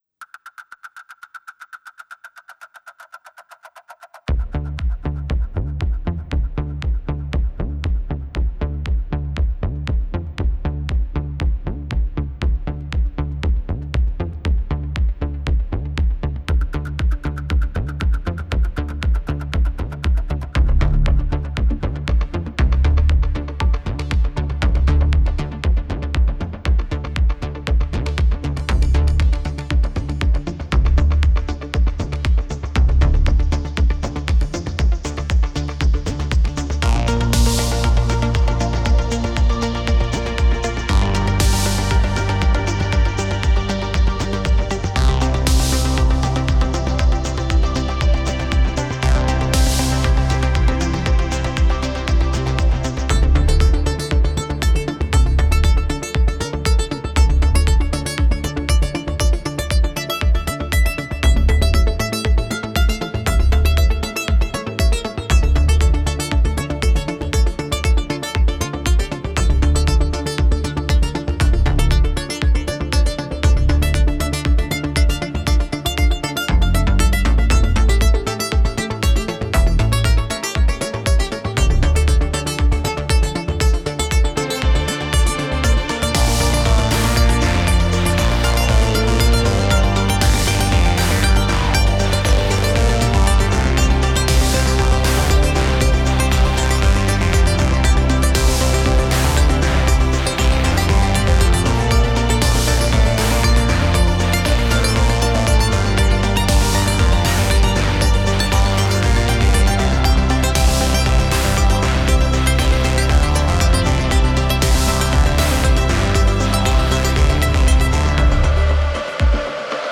und satte Bass-Synths auf Jean-Michel-Jarre-artige Sequenzen